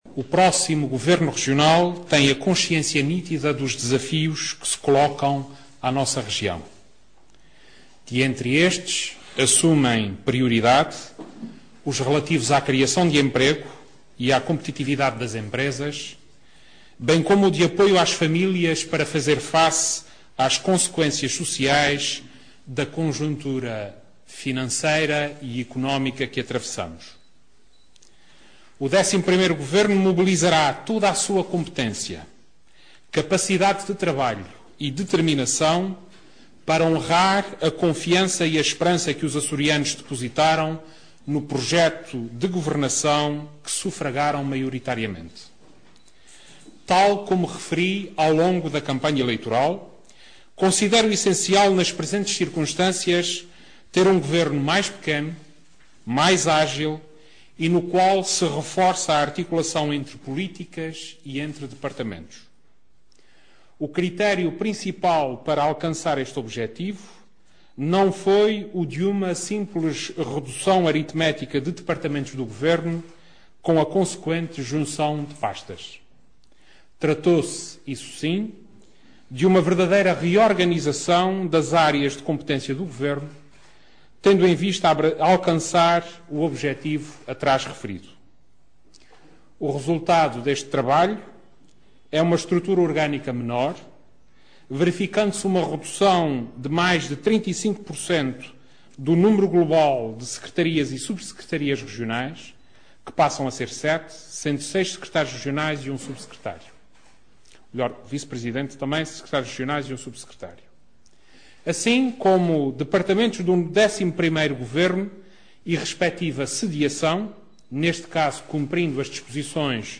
Notícias dos Açores: Intervenção do Presidente do Governo indigitado